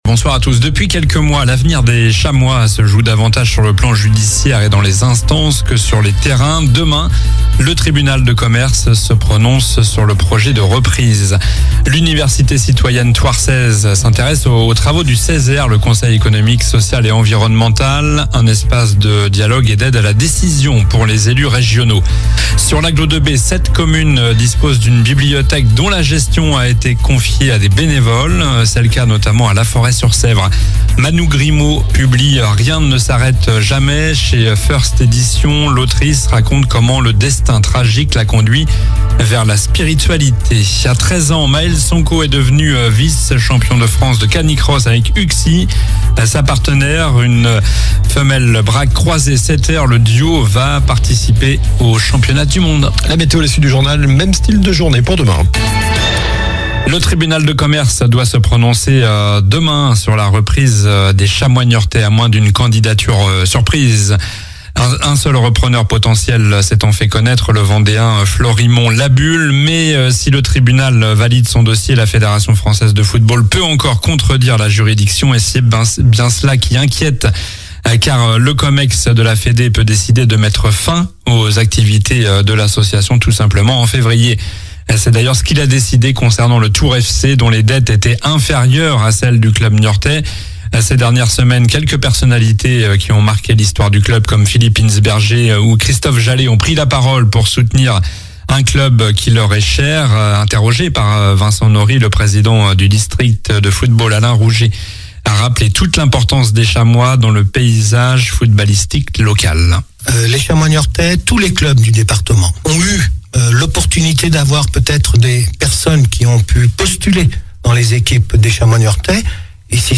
Journal du lundi 10 mars (soir)